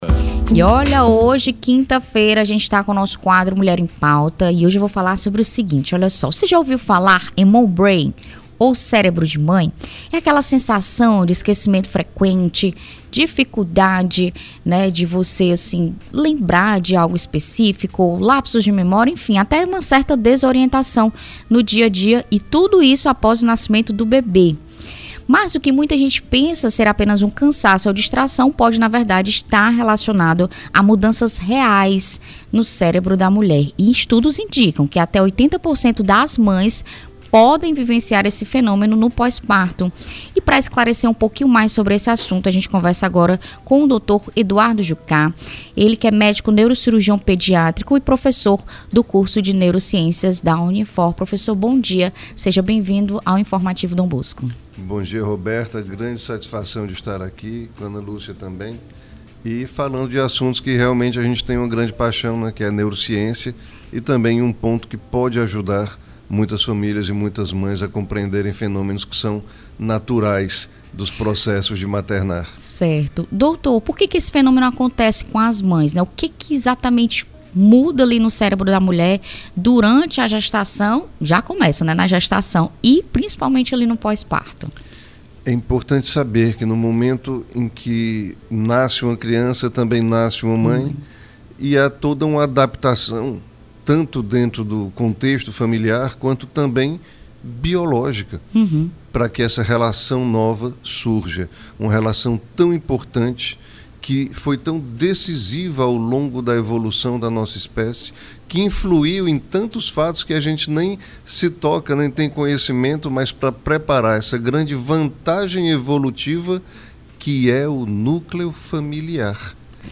ENTREVISTA-0503.mp3